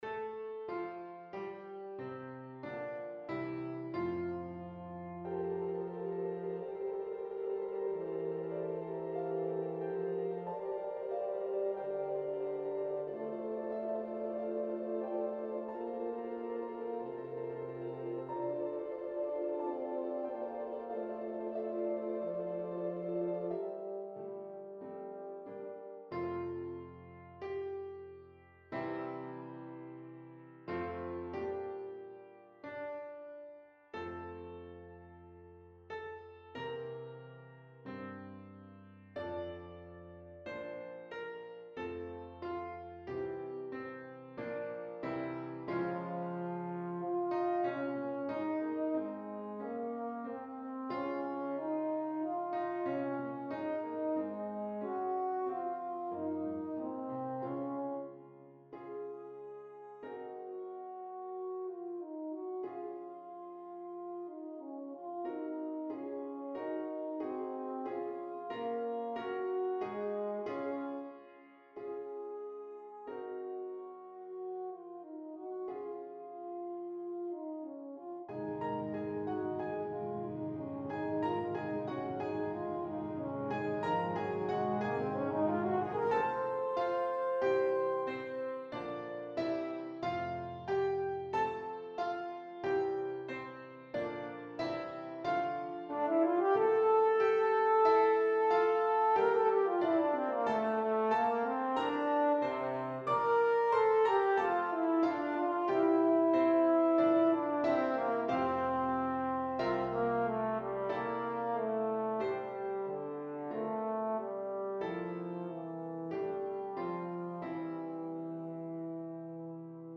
Besetzung: Euphonium Solo & Piano